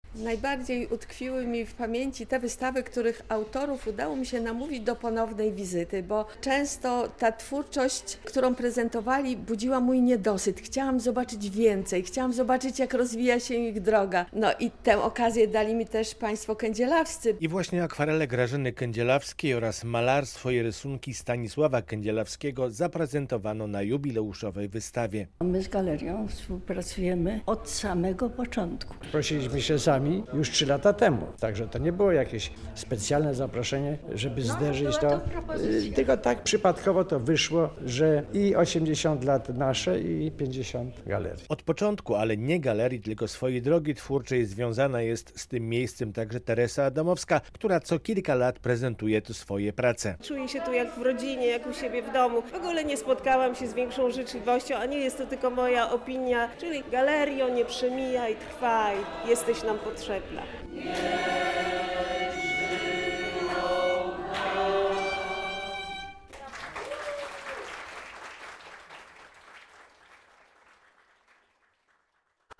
Radio Białystok | Wiadomości | Wiadomości - Galeria Sztuki Współczesnej w Łomży świętuje 50-lecie działalności